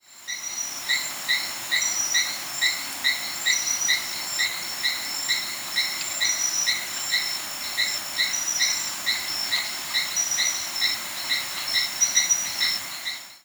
Forest Chirping Frog
The Forest Chirping Frog is a small frog with a loud call.
This is another of the many frogs that make chirping or tink calls.
However, my recording and many others I heard online sound more like a squeaky, raspy "creek" sound.  That may be due to the fact that I was reasonably close to this frog and was using a shotgun microphone?
Adenomera hylaedactyla call